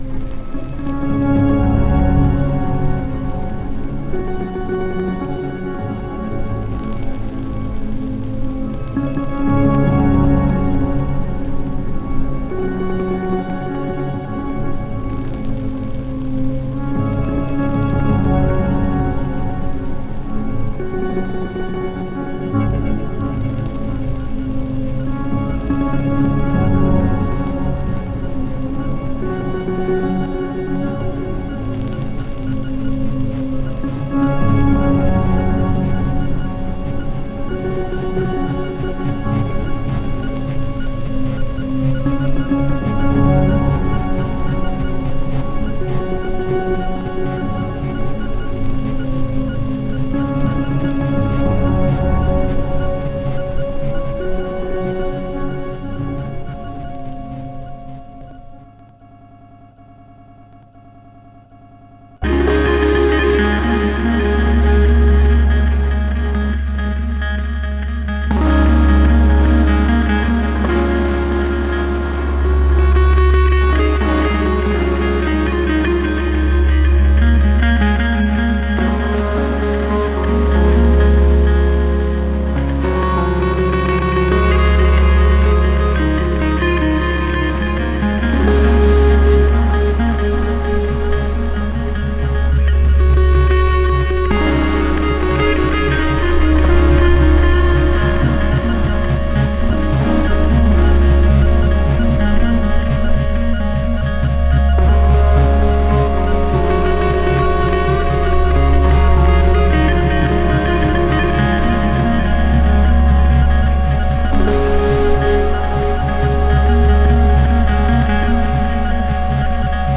dance/electronic
Leftfield/noise
Ambient
IDM